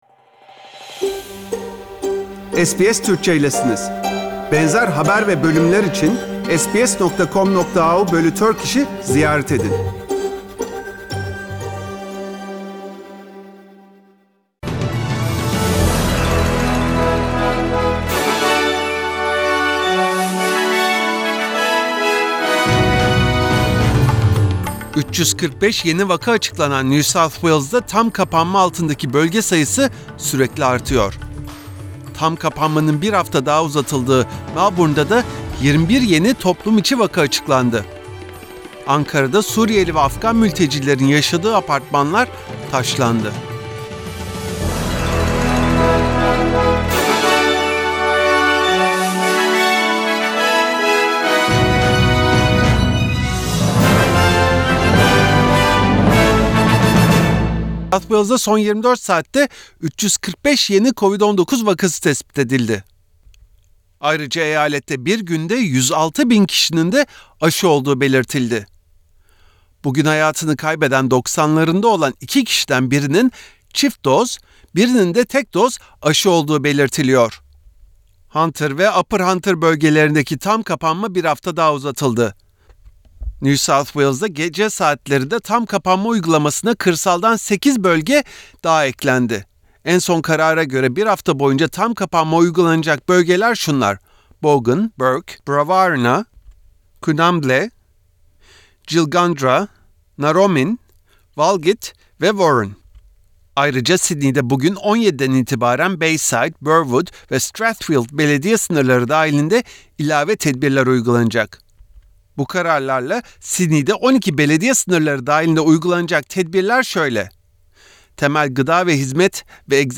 SBS Türkçe Haberler 12 Ağustos